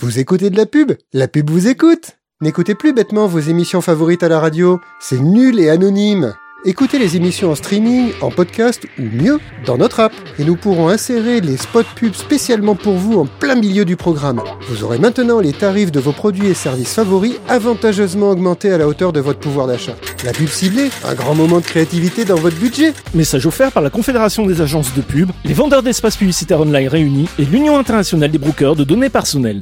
Chroniques › Fausse publicité